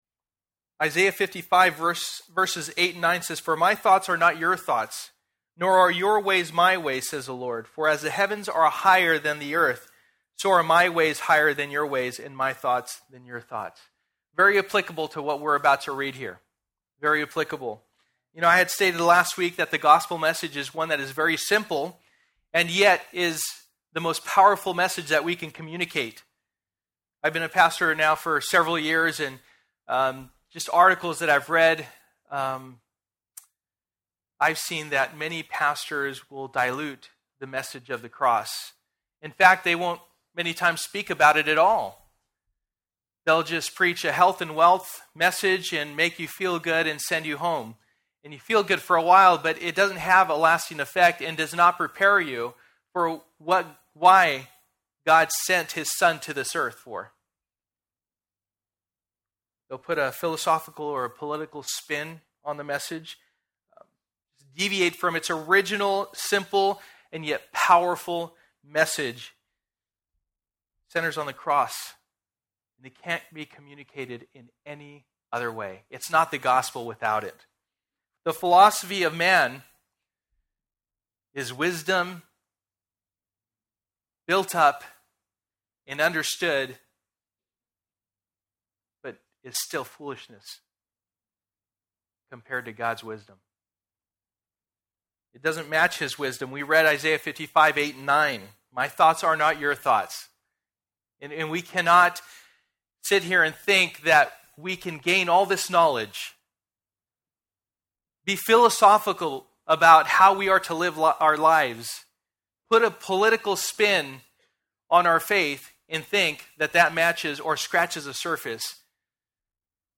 Sold Out Passage: 1 Corinthians 2:1-16 Service: Sunday Morning %todo_render% « Sold Out